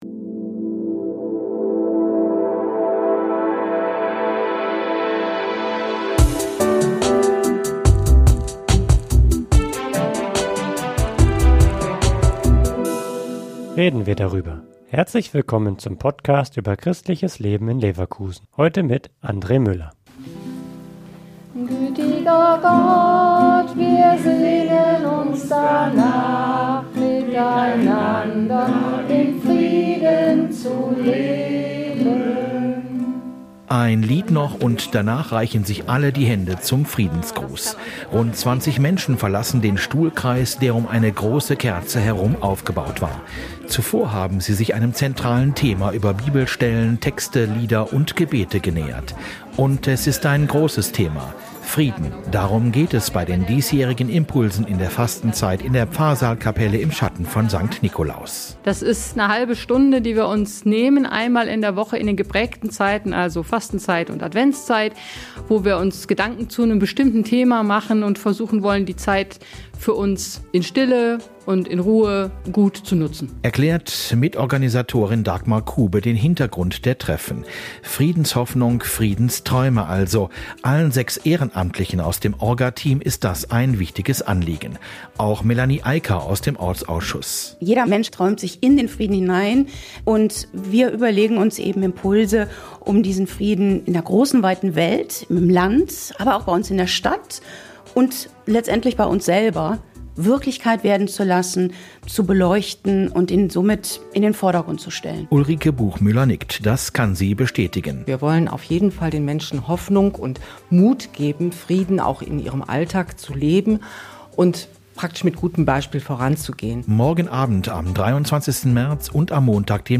im Gespräch mit dem Orga-Team.